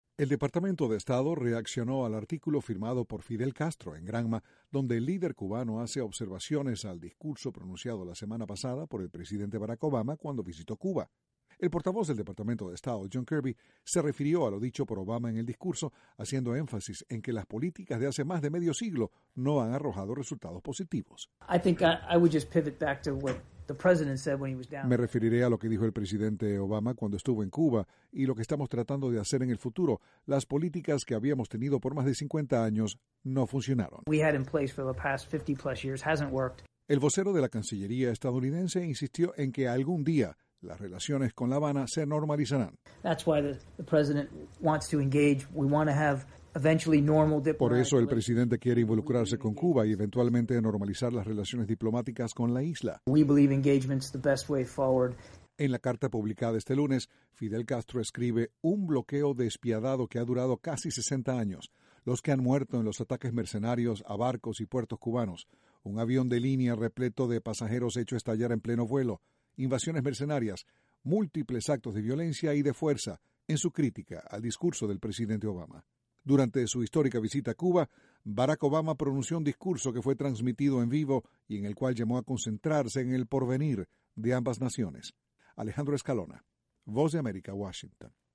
La cancillería estadounidense hizo comentarios sobre la carta firmada por Fidel Castro en la que el líder cubano hace observaciones al discurso de Barack Obama, la semana pasada, en la isla. Desde la Voz de América, Washington, informa